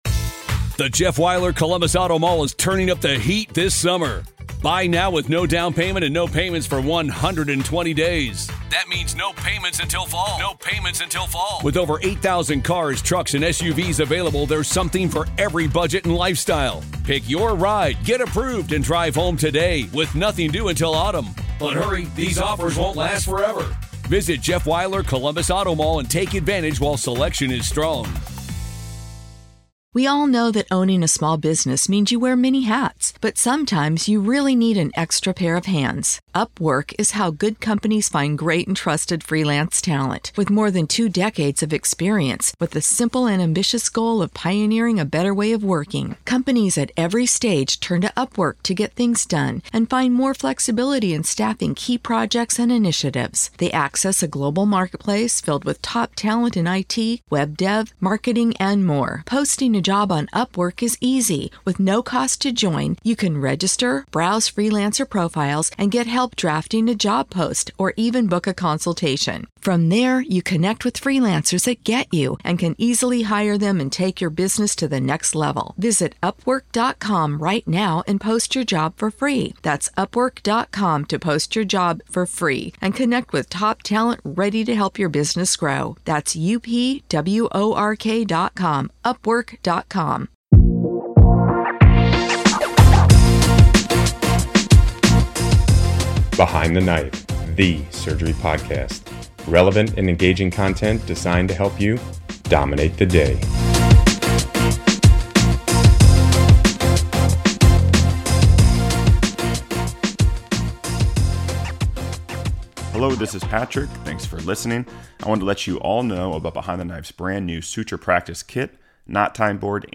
In this two-part series, we come to you LIVE! from the 2023 Annual meeting of the American Association of Endocrine Surgeons in Birmingham, Alabama.